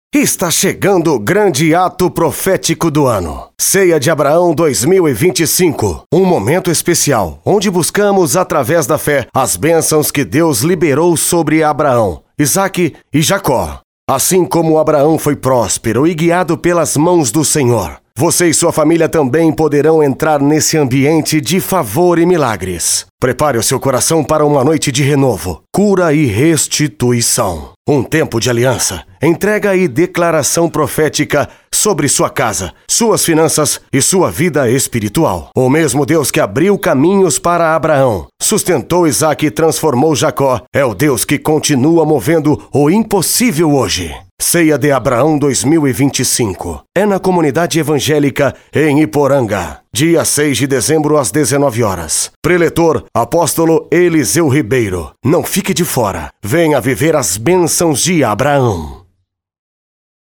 OFF PARA IGREJA MEDIO IMPACTO: